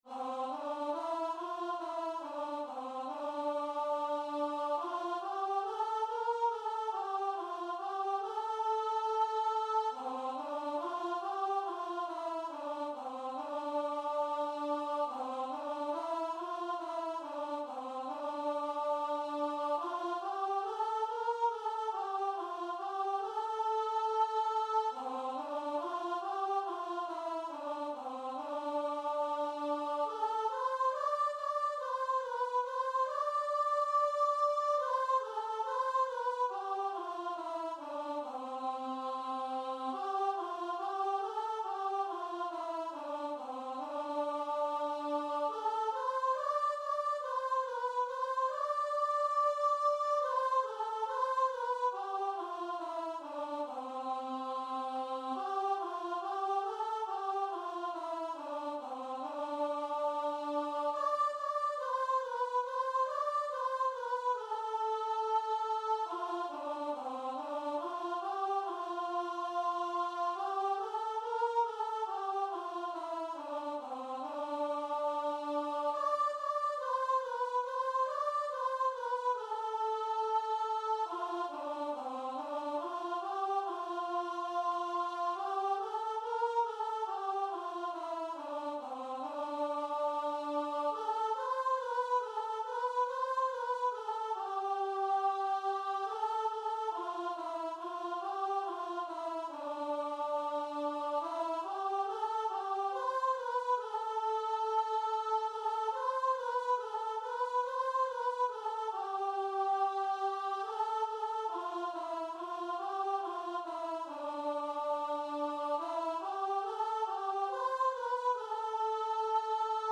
Traditional Music of unknown author.
C major (Sounding Pitch) (View more C major Music for Choir )
4/4 (View more 4/4 Music)
Choir  (View more Easy Choir Music)
Christian (View more Christian Choir Music)